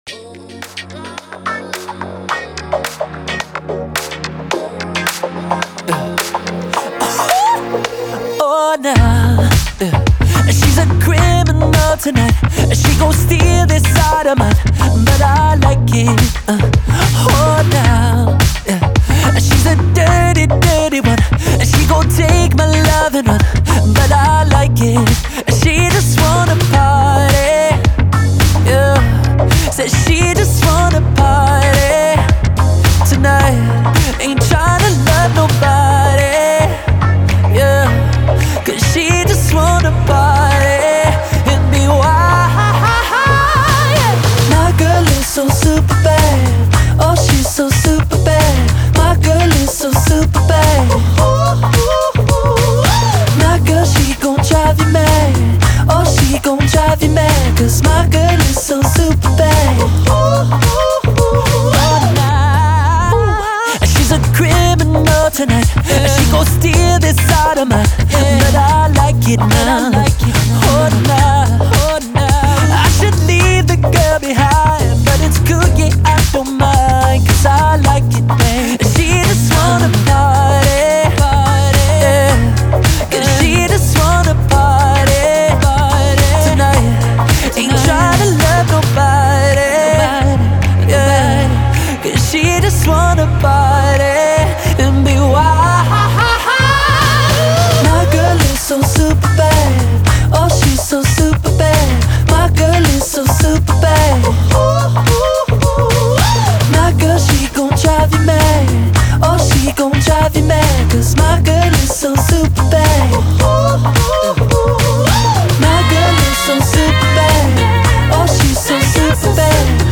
это зажигательная поп-музыка с элементами фанк и R&B